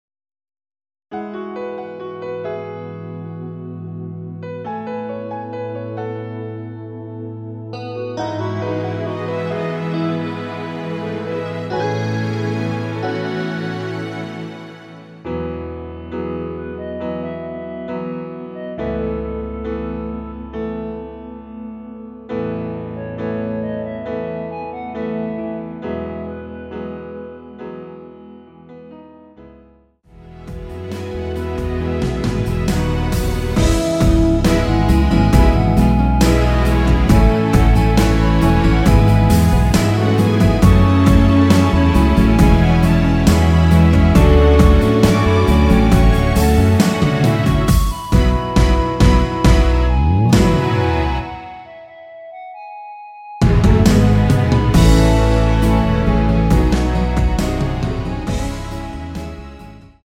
원키에서(-2)내린 멜로디 포함된 MR입니다.(미리듣기 참조)
앞부분30초, 뒷부분30초씩 편집해서 올려 드리고 있습니다.
중간에 음이 끈어지고 다시 나오는 이유는